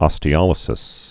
(ŏstē-ŏlĭ-sĭs)